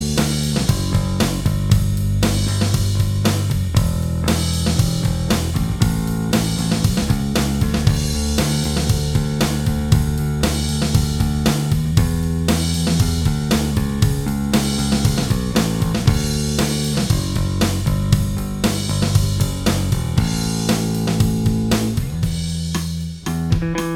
Minus All Guitars Rock 3:36 Buy £1.50